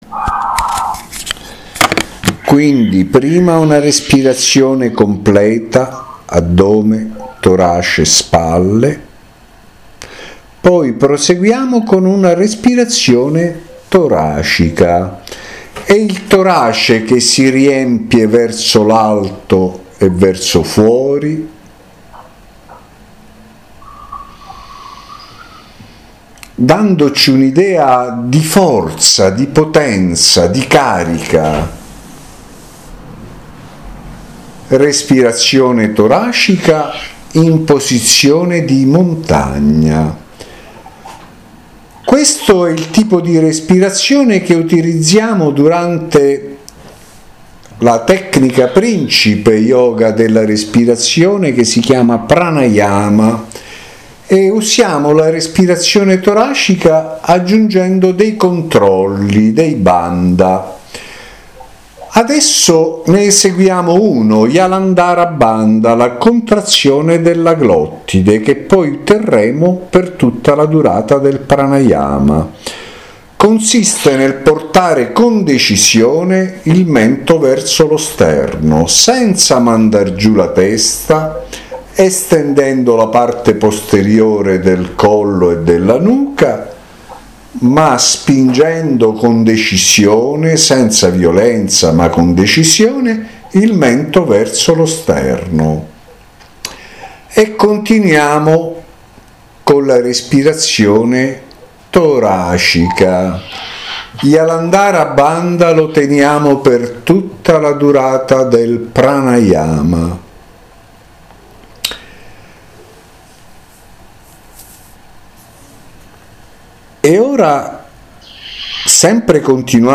Corso di meditazione – Lezione 6